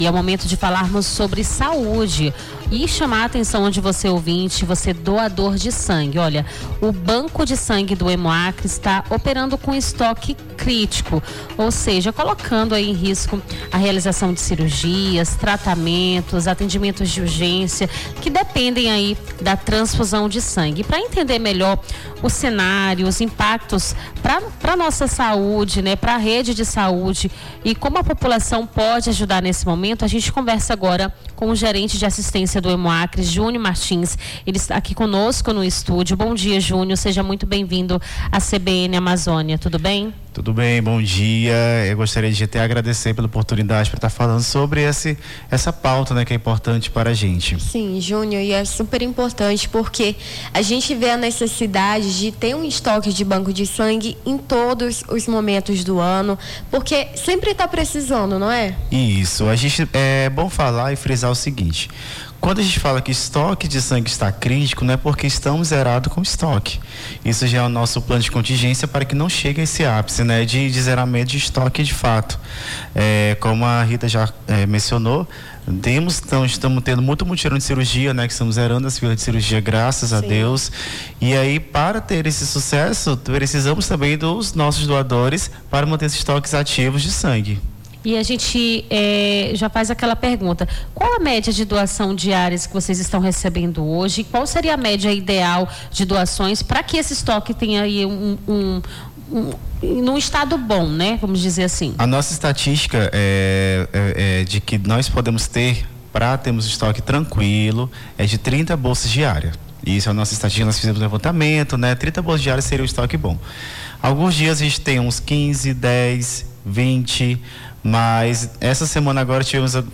Nome do Artista - CENSURA - ENTREVISTA HEMOACRE (22-05-25).mp3